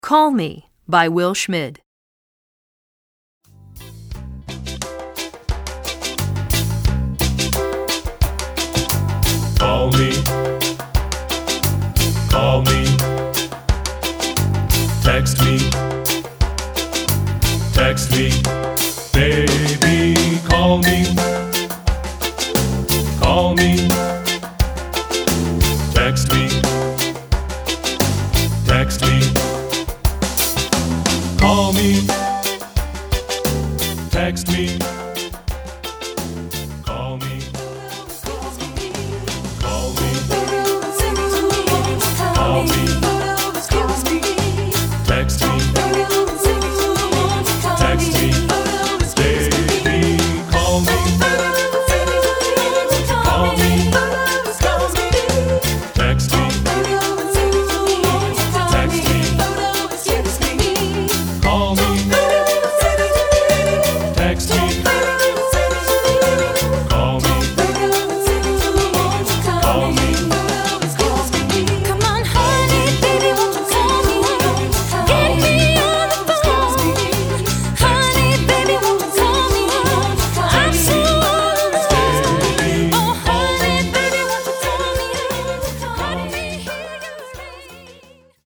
Voicing: ShowTrax CD